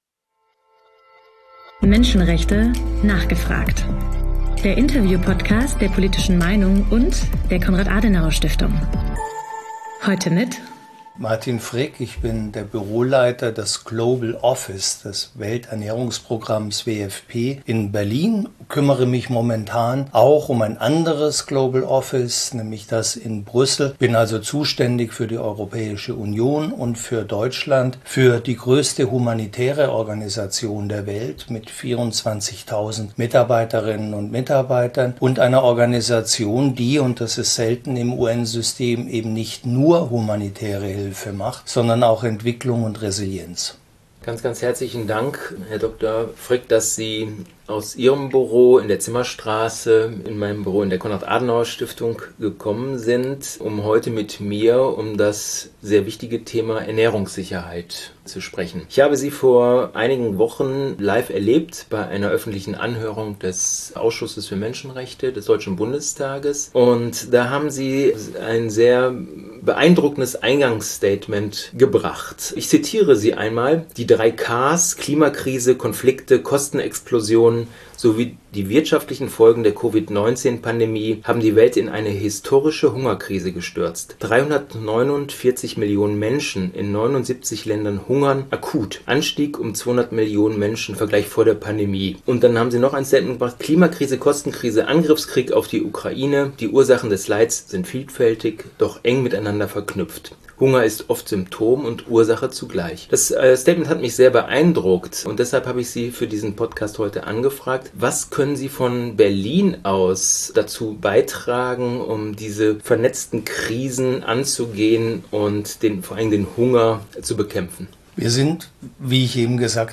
Menschenrechte: nachgefragt! - Der Interview-Podcast rund ums Thema Menschenrechte